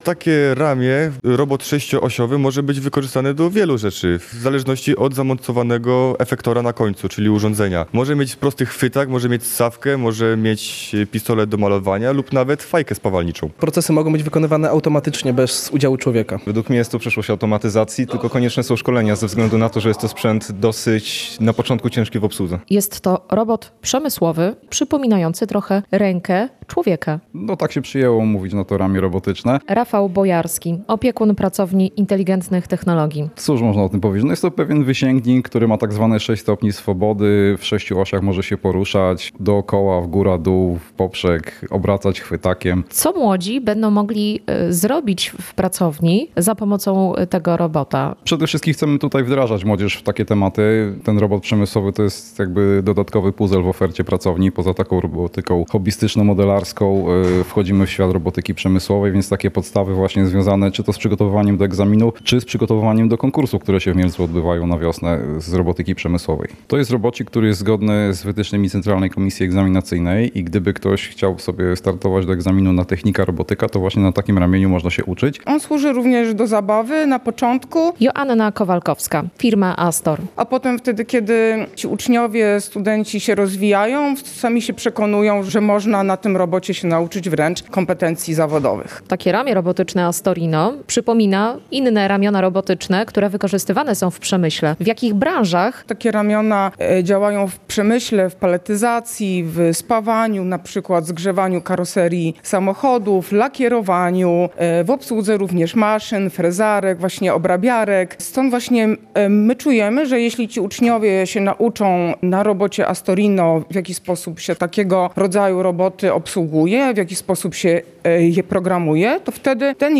Relacje reporterskie • Nowoczesne ramię robotyczne trafiło do Pracowni Inteligentnych Technologii w Podkarpackim Centrum Innowacji w Rzeszowie.